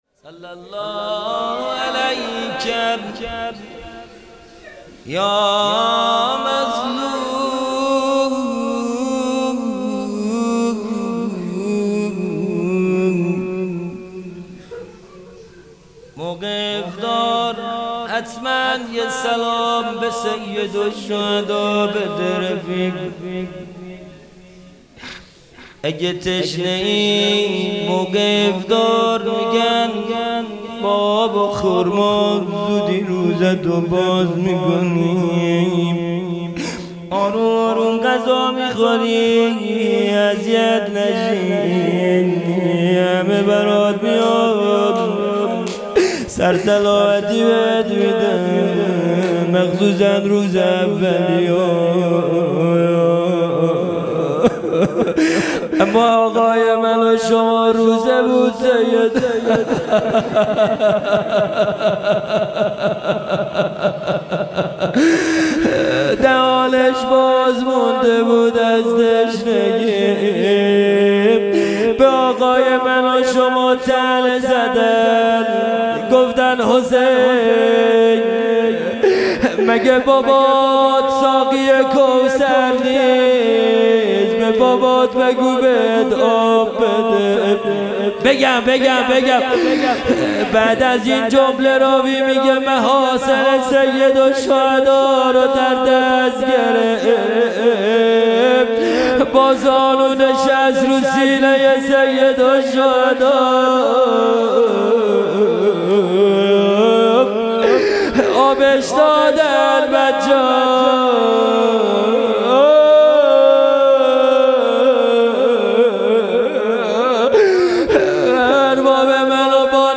مناجات پایانی | صل الله علیک یا مظلوم | 18 فروردین 1401
جلسۀ هفتگی | مناجات ماه رمضان | پنج شنبه 18 فروردین 1401